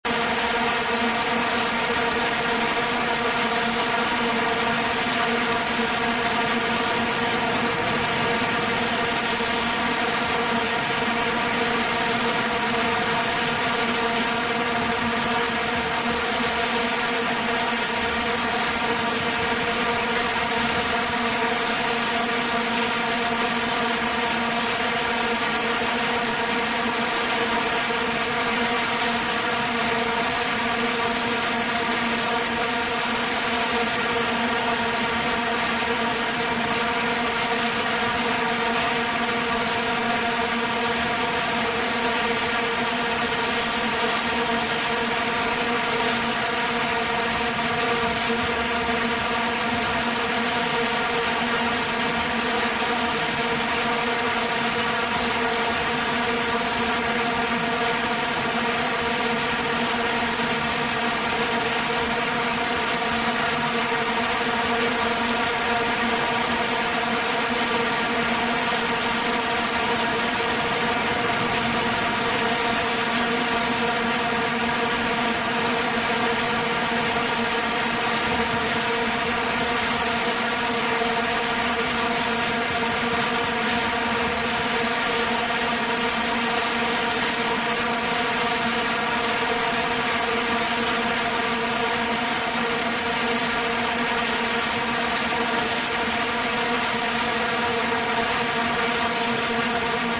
mp3 za  £2.53 jako vyzváněcí tón, která údajně zvuk vuvuzely vyruší, je to pochopitelně scam - bučení vuvuzely jen tak něco neodolá...
antivuvuzelafilter.mp3